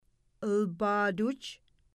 Mi’kmaw Pronunciations for Teaching About the Mi’kmaq